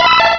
pokeemerald / sound / direct_sound_samples / cries / seadra.aif
-Replaced the Gen. 1 to 3 cries with BW2 rips.